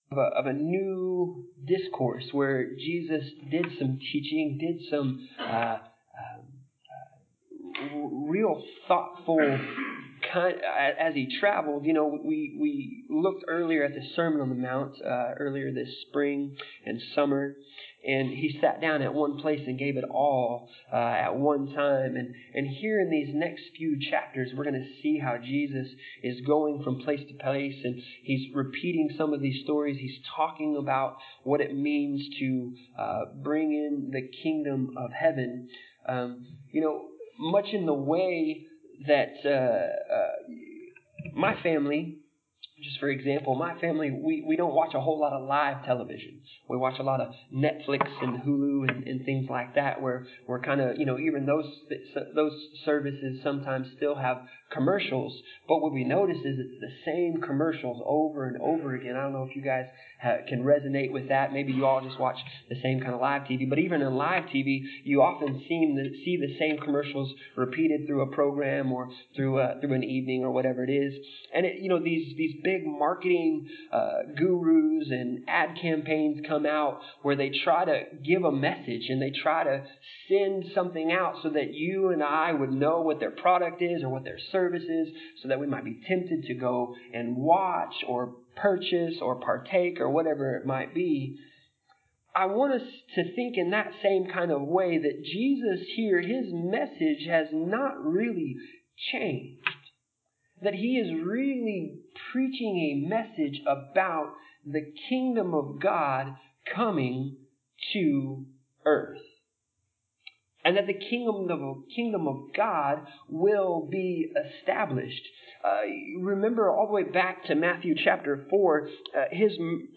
Passage: Matthew 13:1-23 Service Type: Sunday Morning